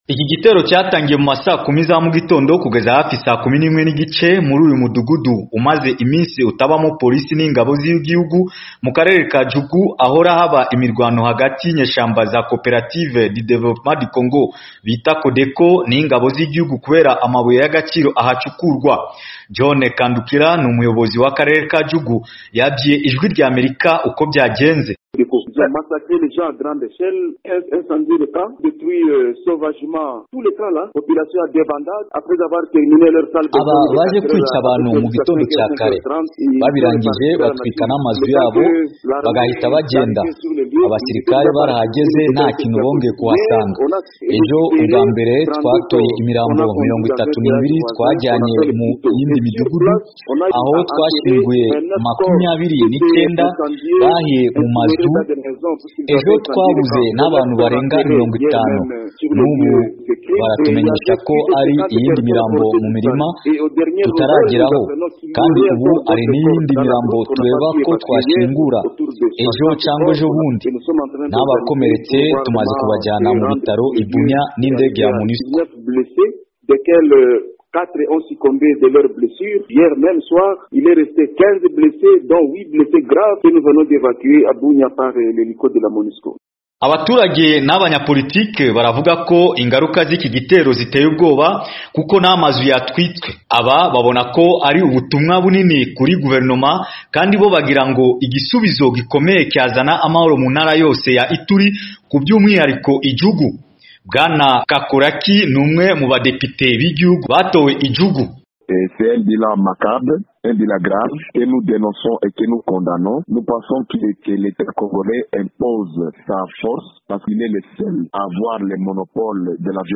Inkuru